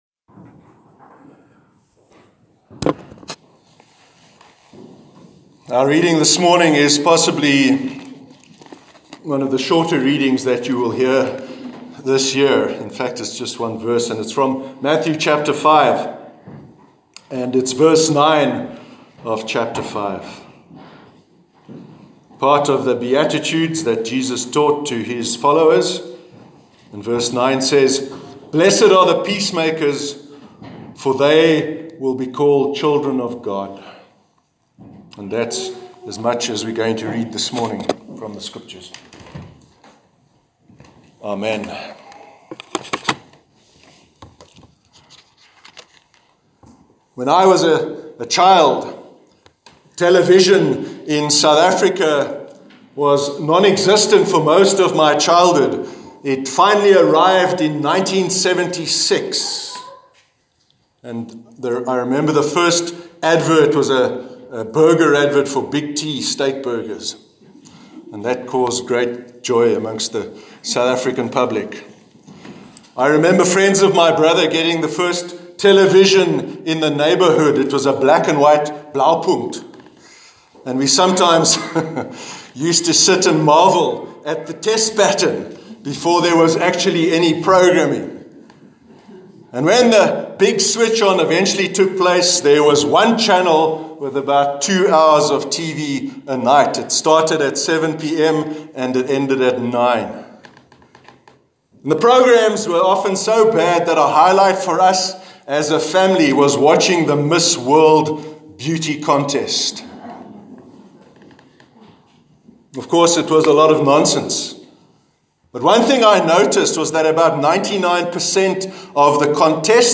Sermon on Peace- 11th February 2018